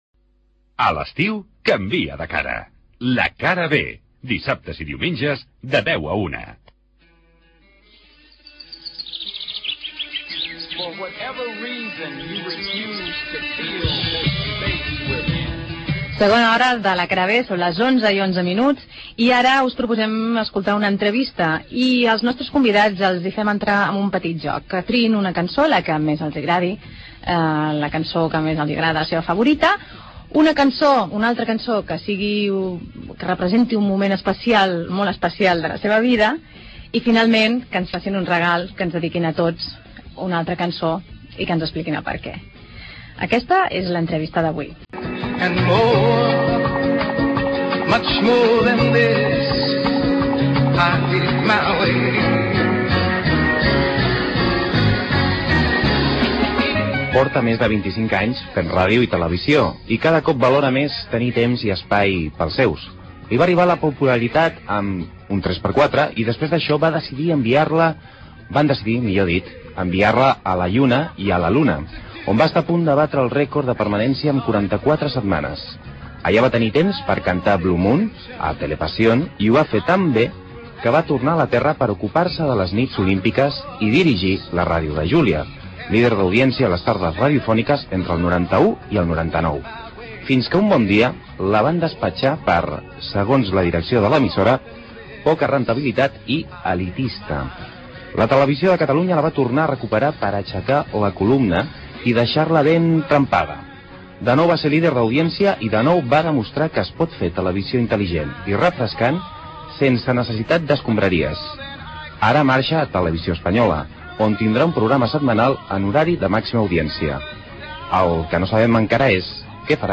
Indicatiu del programa, hora, tema musical, perfil professional de Julia Otero i entrevista a la presentadora sobre la seva feina
Entreteniment